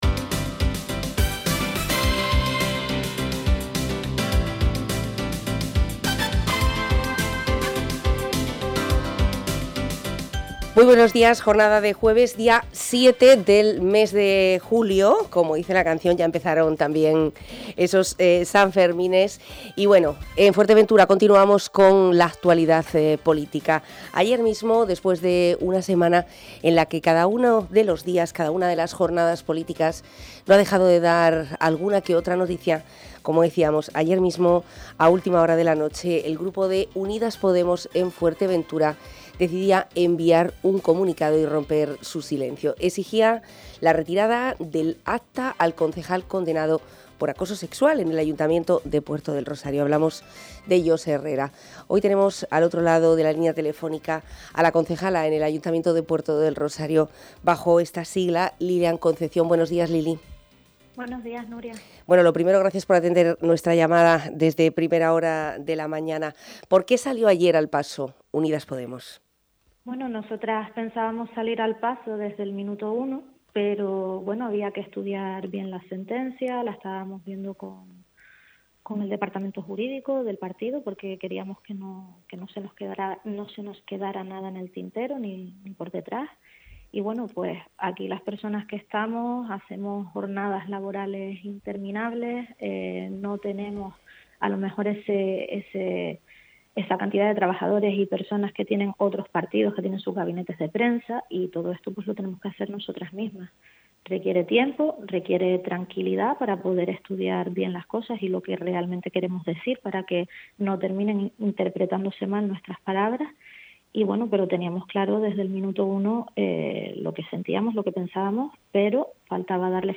La concejala de Podemos en Puerto del Rosario Lili Concepción ha estado en los micrófonos de Onda Fuerteventura y en El Magacín ha hablado del comunicado de su formación en torno a los últimos acontecimientos en el Ayuntamiento Capitalino.